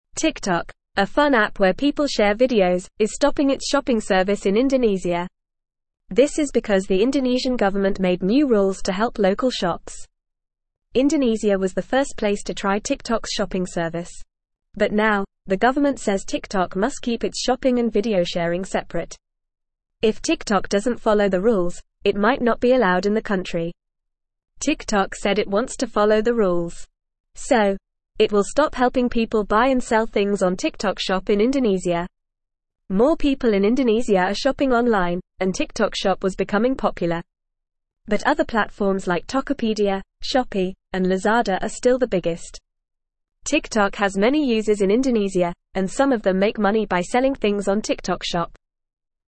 Fast
English-Newsroom-Beginner-FAST-Reading-TikTok-stops-shopping-in-Indonesia-to-follow-rules.mp3